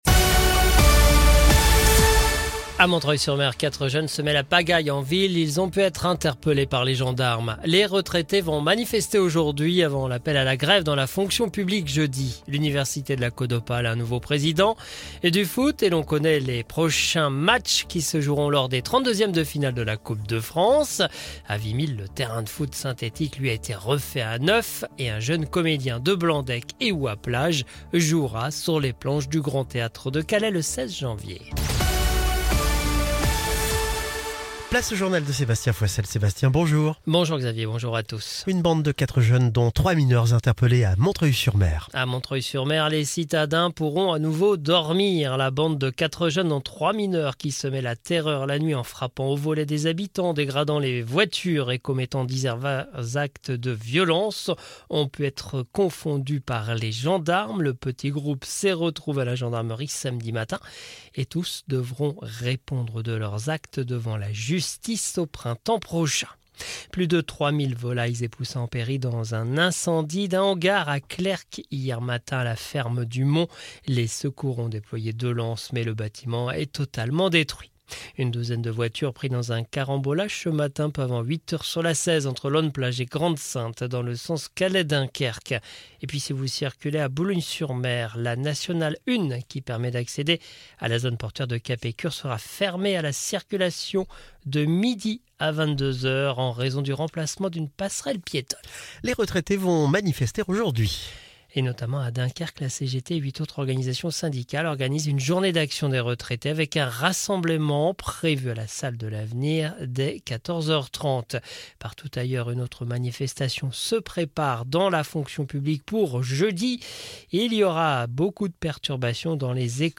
Le journal du mardi 3 décembre 2024